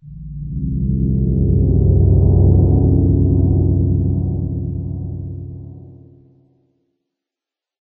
cave3.ogg